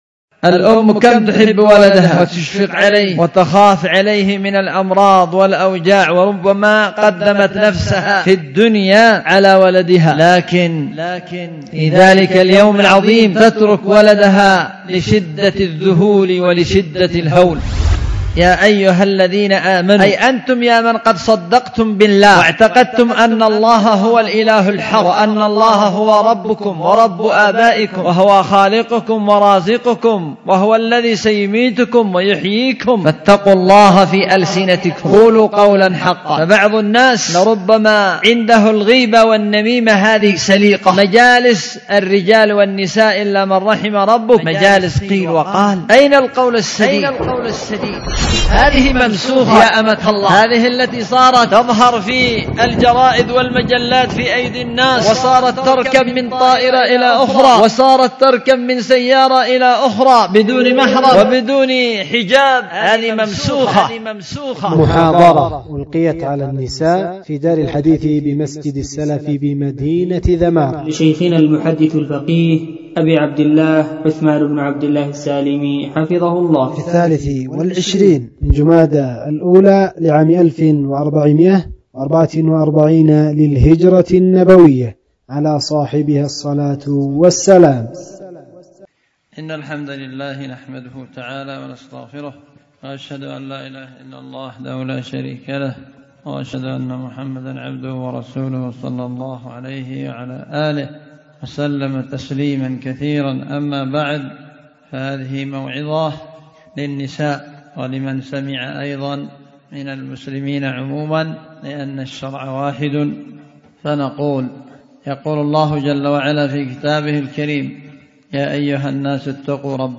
محاضرة: ألقيت على النساء